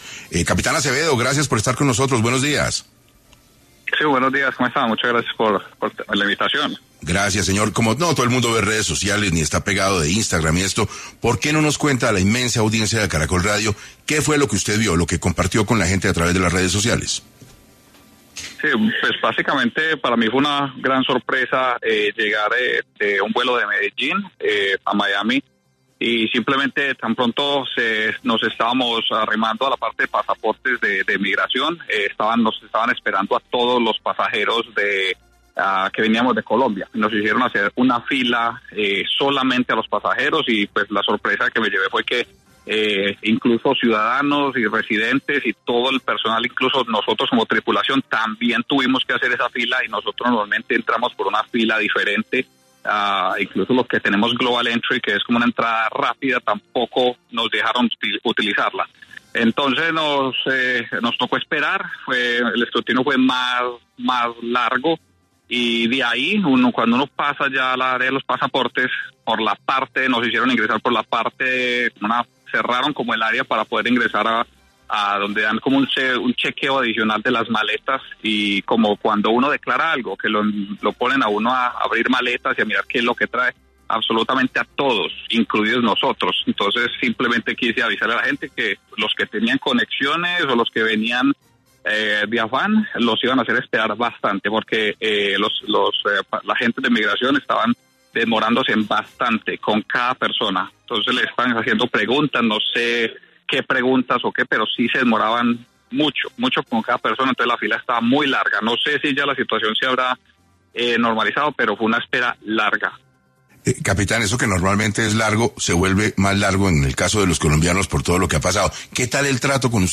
Piloto colombiano relata su experiencia con nuevas inspecciones reforzadas en vuelos hacia EE. UU.